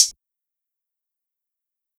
Closed Hats
hihat 12.wav